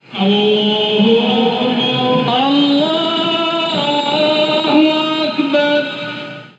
Rezo musulmán
rezo
Sonidos: Voz humana